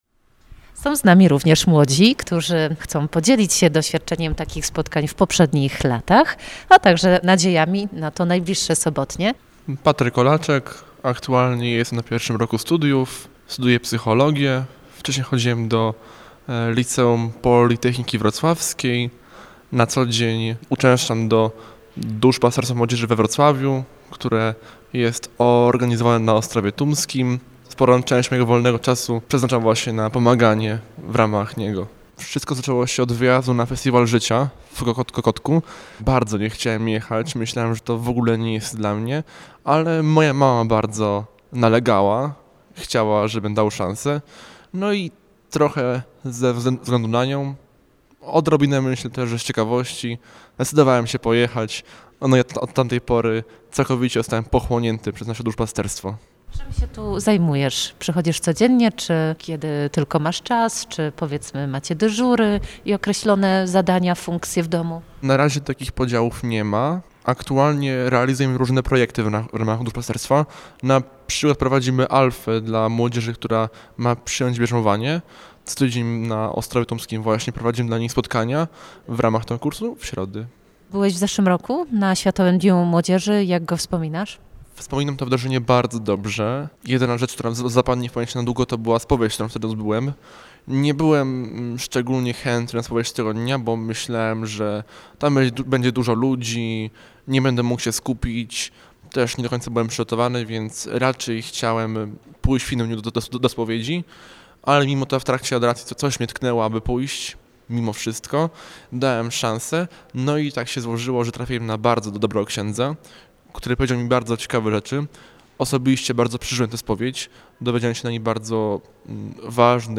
Emisja rozmowy w piątek po godz. 10:10.